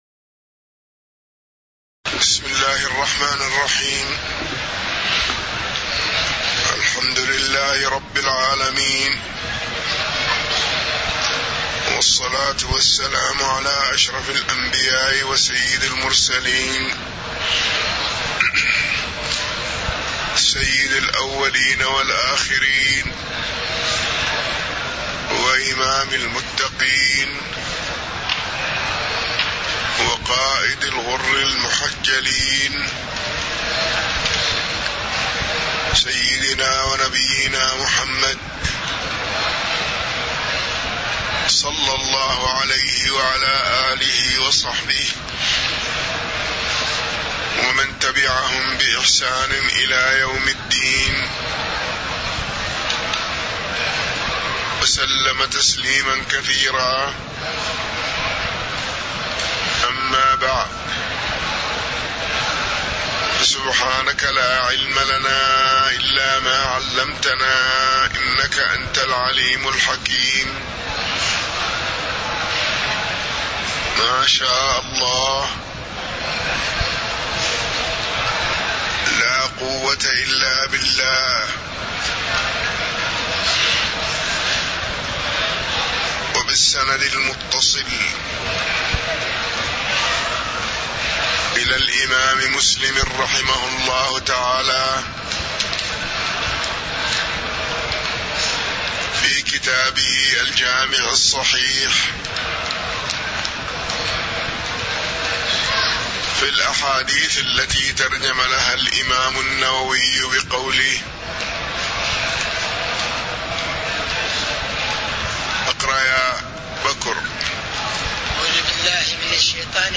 تاريخ النشر ١٩ شوال ١٤٣٧ هـ المكان: المسجد النبوي الشيخ